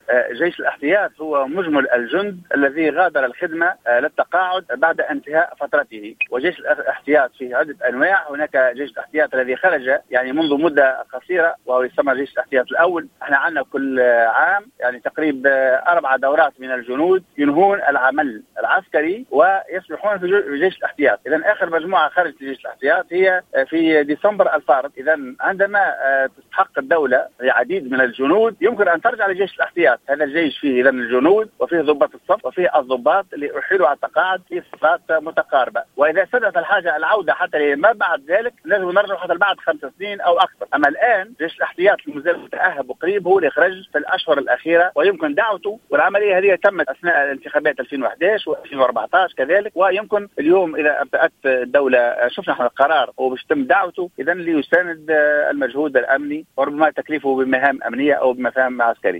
في تصريح لجوهرة "اف ام"